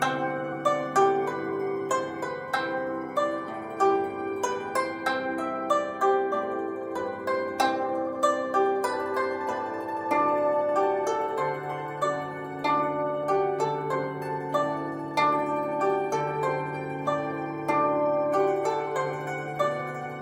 无人机Hi Pad 4
Tag: 80 bpm Ambient Loops Pad Loops 2.20 MB wav Key : Unknown